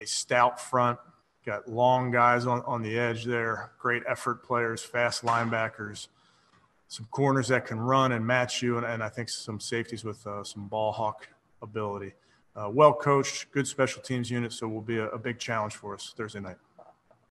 Browns head coach Stefanski spoke on the Bengals offense: